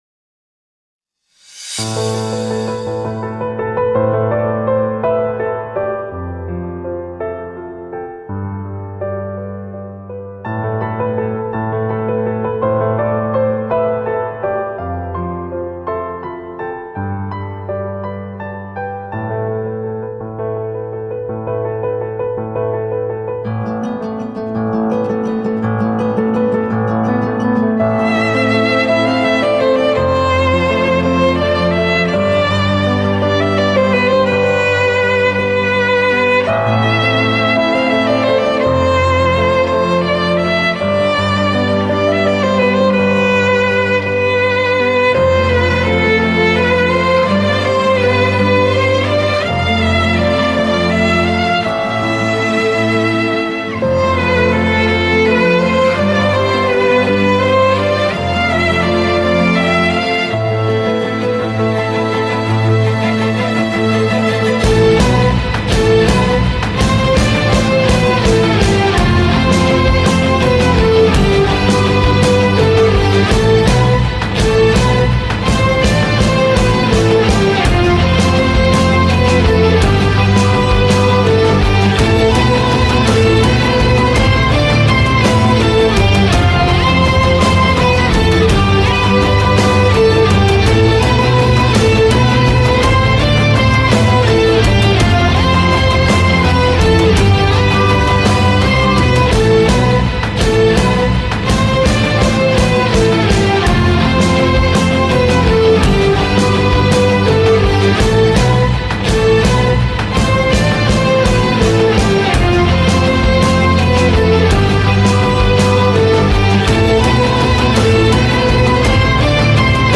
tema dizi müziği, duygusal mutlu rahatlatıcı fon müziği.